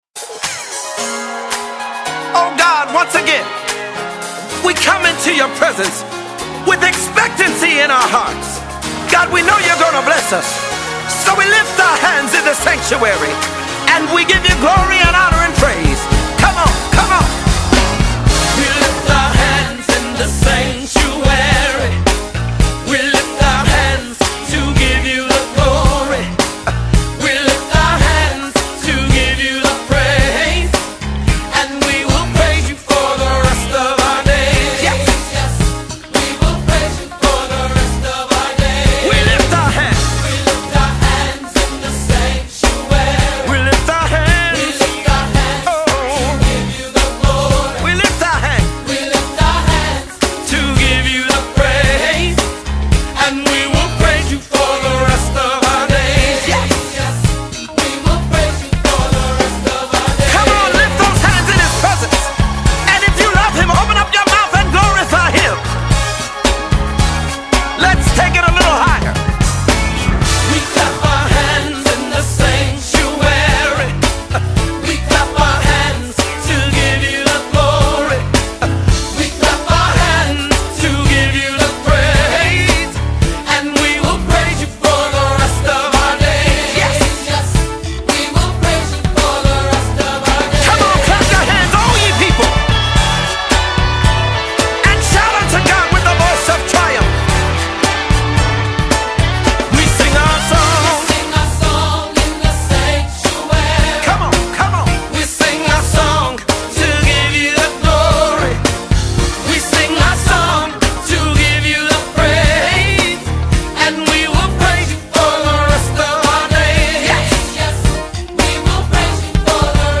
Orchestra/Choir